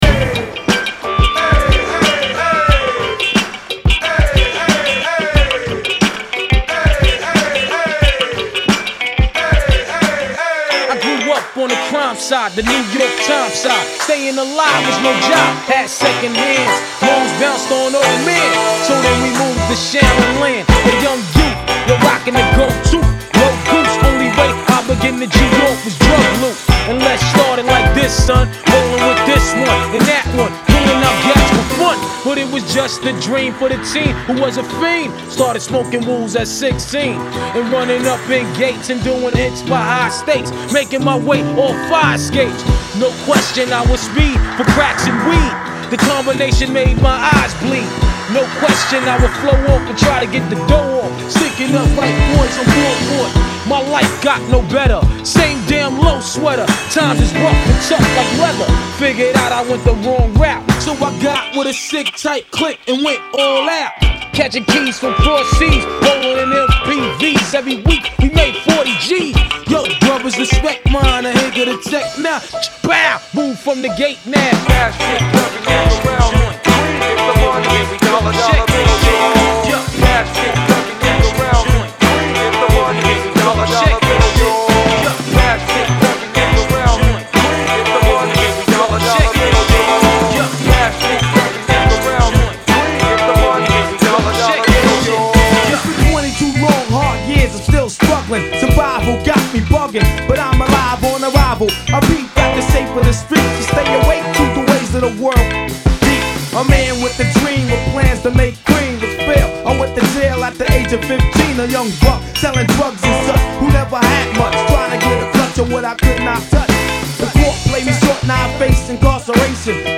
I’m not usually one for instrumentals
retro meets other-worldly flavor